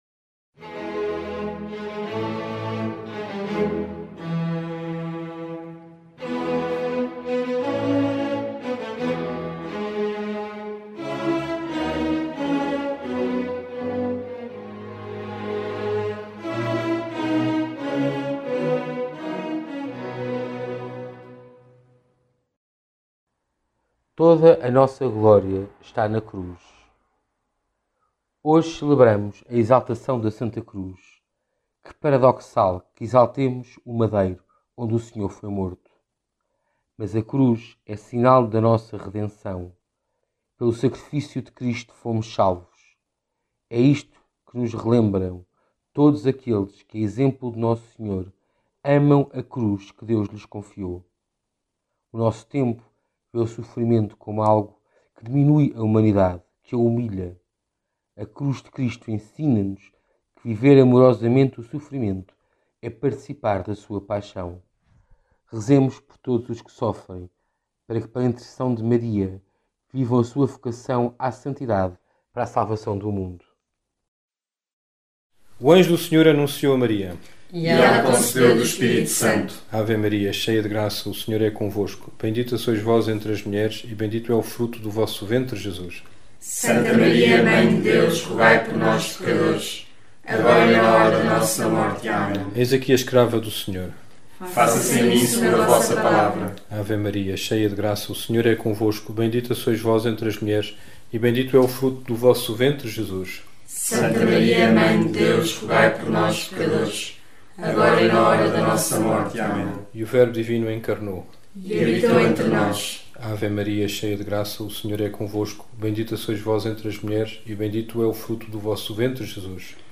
Angelus, Meditações Diárias